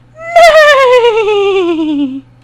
infinitefusion-e18/Audio/SE/Cries/RAPIDASH.mp3 at releases-April